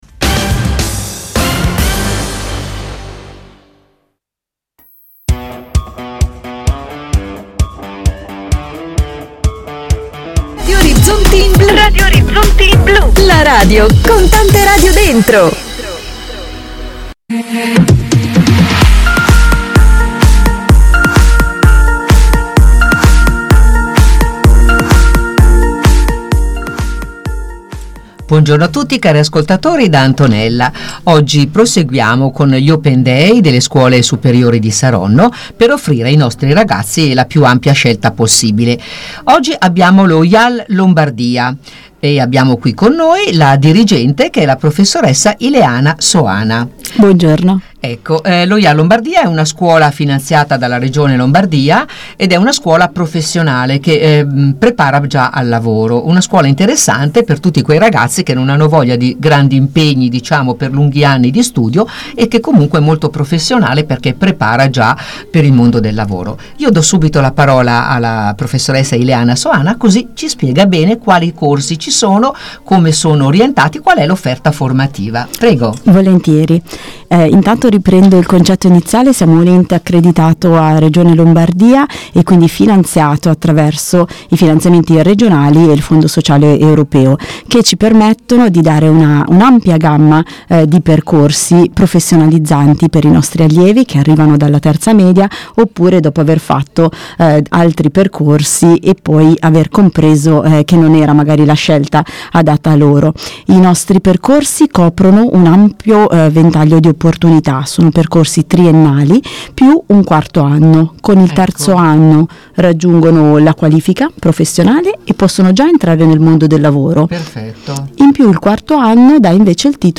Radiorizzonti, emittente locale che trasmette da Saronno con un segnale che copre tutto l’Alto Milanese, ha ospitato questa mattina nei suoi studi
openday-radioorizzonti.mp3